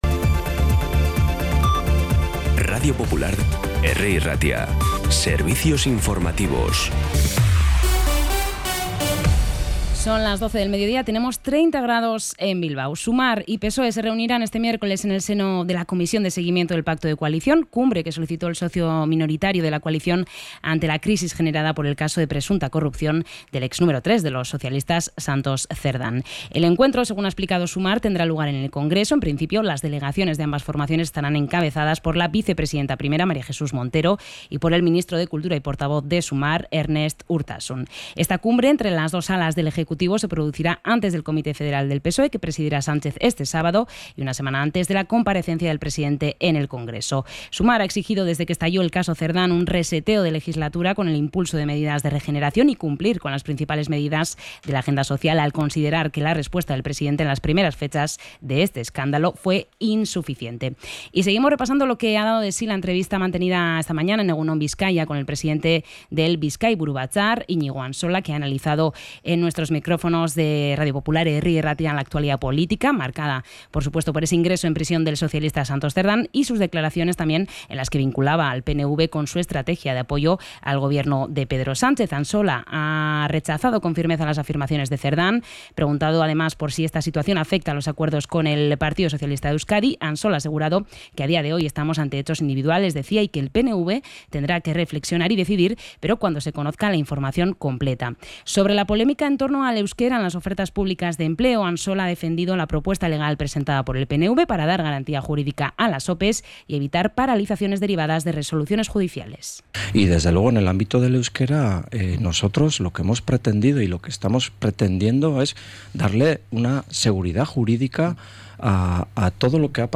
Boletín informativo de las 12 h